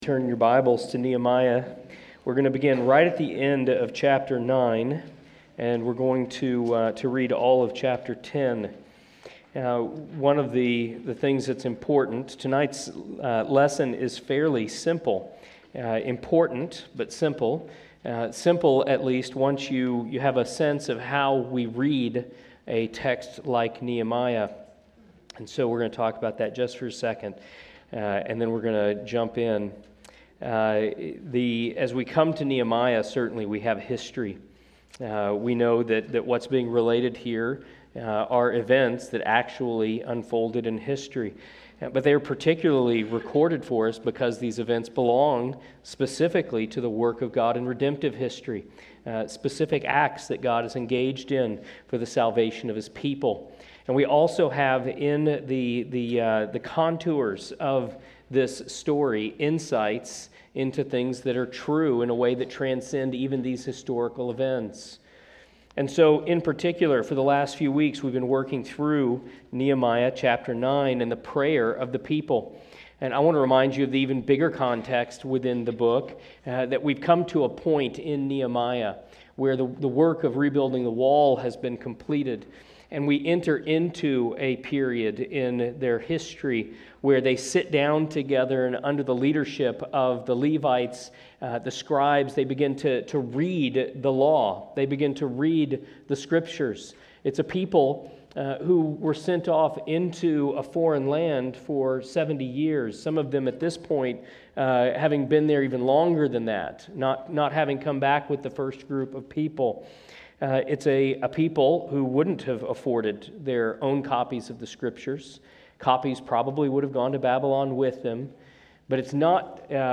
Sermons and Lessons from All Saints Presbyterian Church (PCA) in Brentwood, TN.